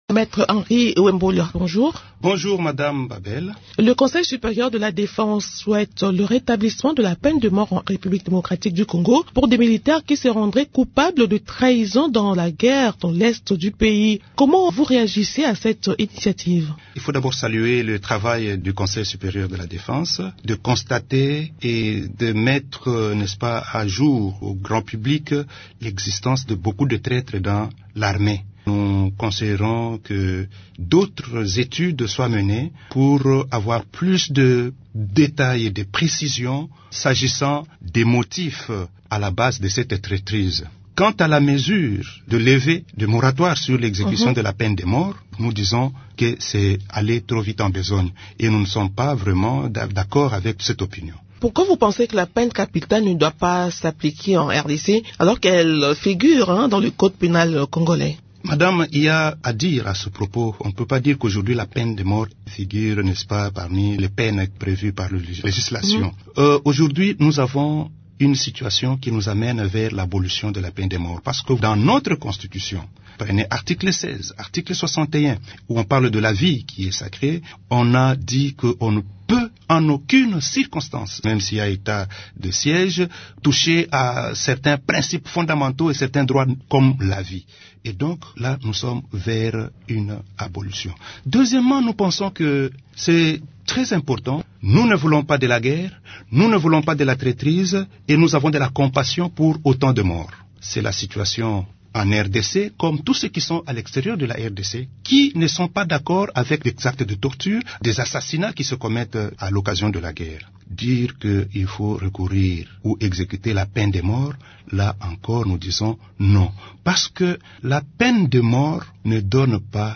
Dans cet entretien